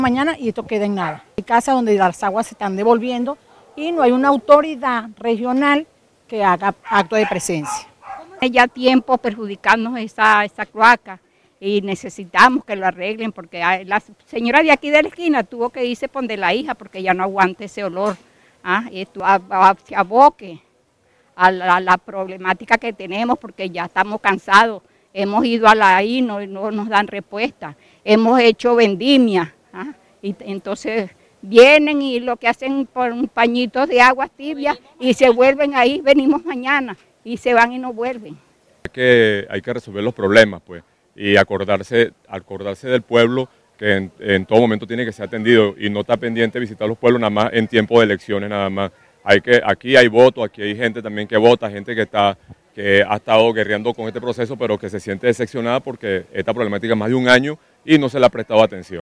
Escuche las declaraciones de los afectados: